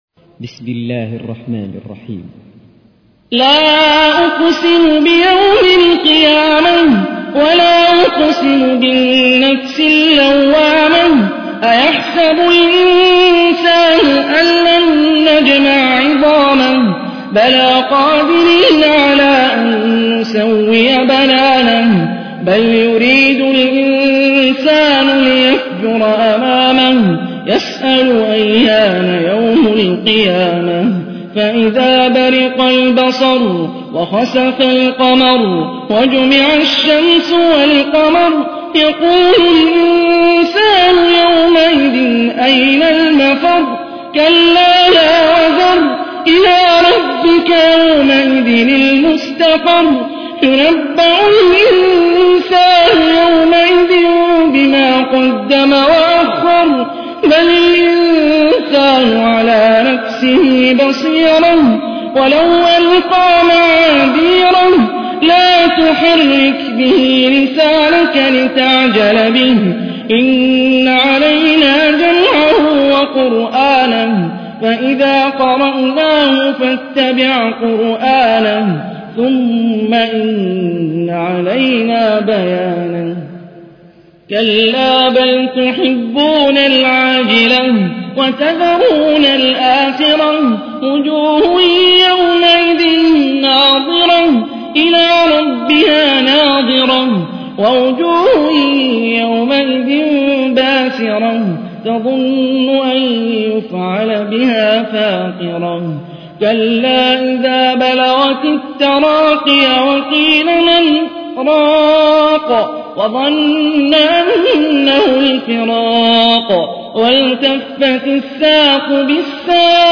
تحميل : 75. سورة القيامة / القارئ هاني الرفاعي / القرآن الكريم / موقع يا حسين